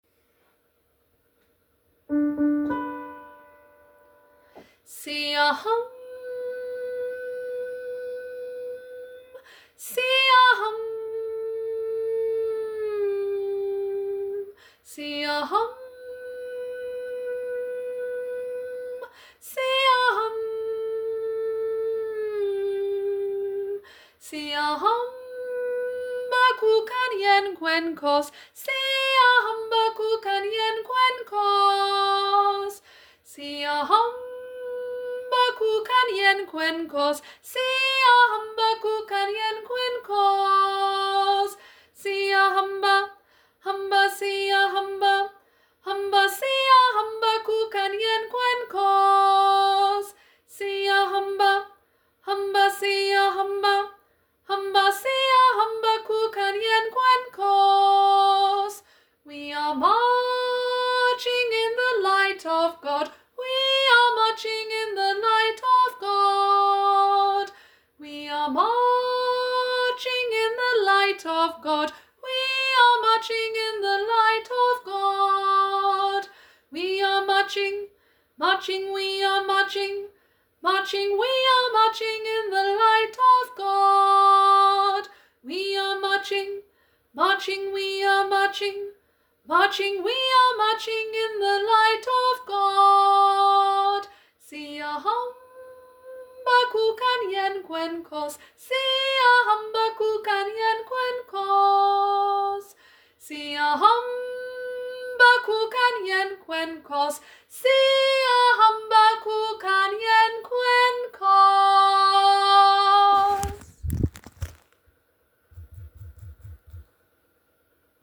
Siyahamba- Soprano (main)
Siyahamba-Soprano-main.m4a